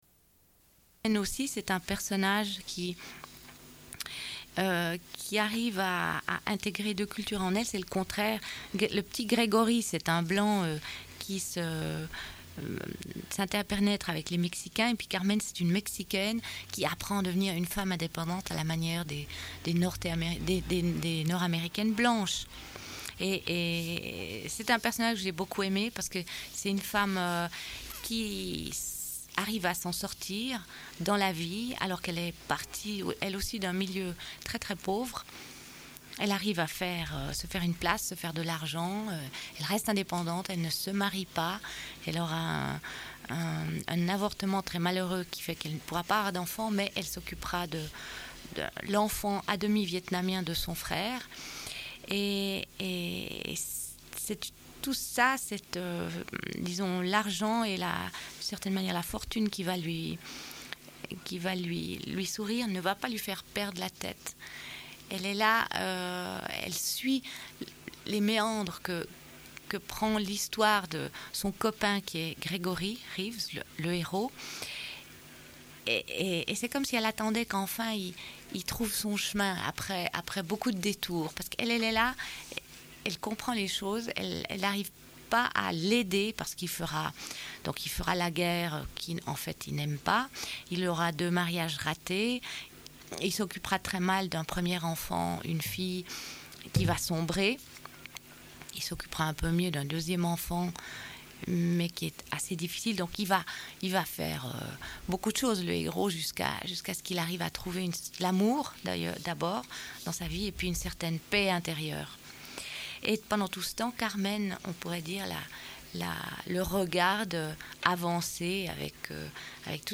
Une cassette audio, face A31:28